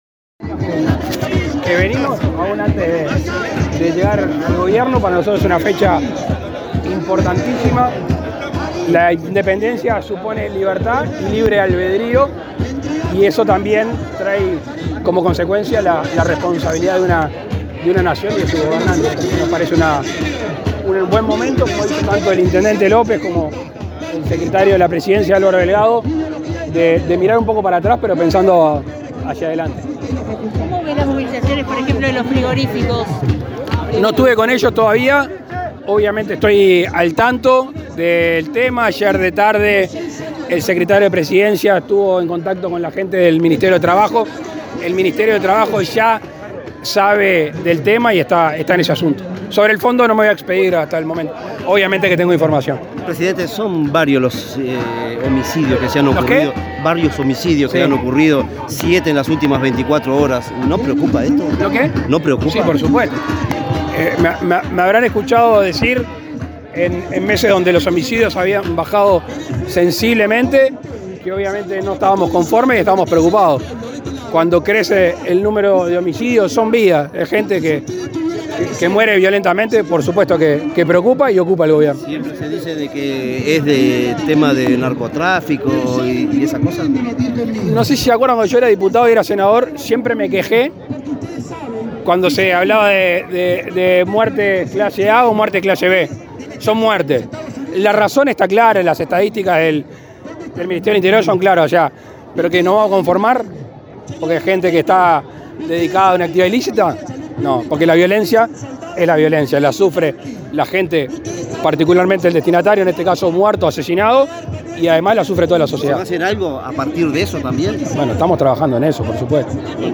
Declaraciones del presidente Lacalle Pou a la prensa
Luego, dialogó con la prensa.